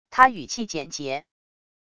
她语气简洁wav音频